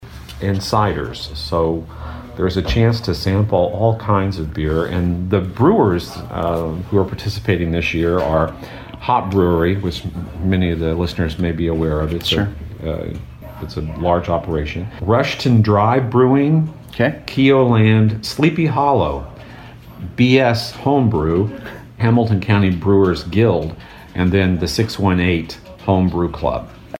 Fred Vallowe, McLeansboro City Clerk, was a guest on WROY’s Open Line program recently and explained how the event came about…